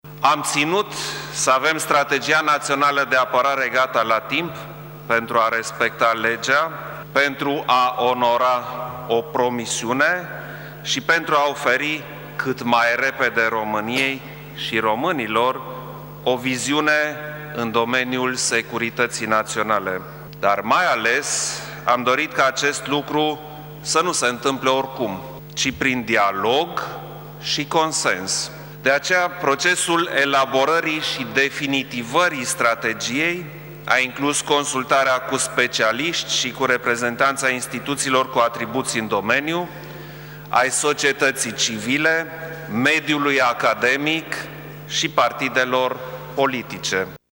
Klaus Iohannis a spus, în discursul susţinut în faţa parlamentarilor, că una dintre principalele sale promisiuni a fost că va prezenta Parlamentului în primele şase luni de la preluarea mandatului Strategia Naţională de Apărare a Ţării.